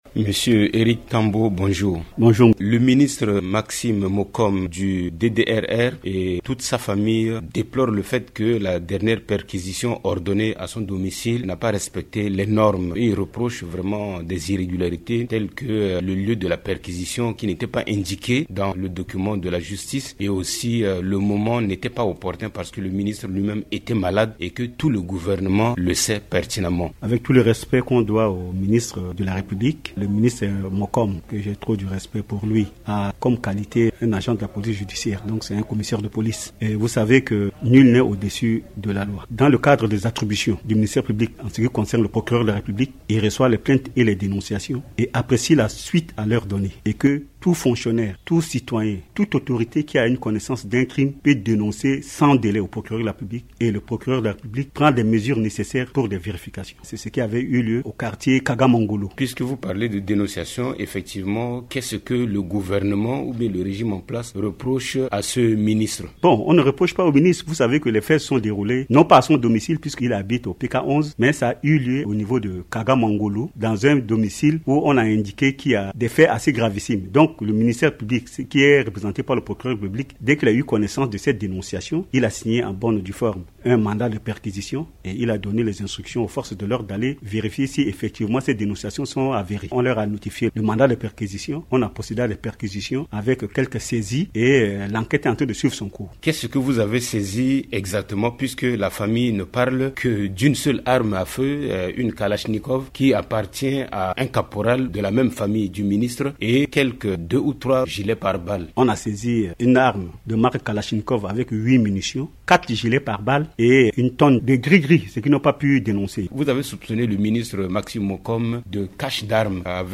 Le procureur général près la Cour d’Appel de Bangui, au cours d’un échange avec Radio Ndeke Luka, clarifie l’opinion sur la perquisition effectuée dans l’un des domiciles privés du ministre Maxime Mokom.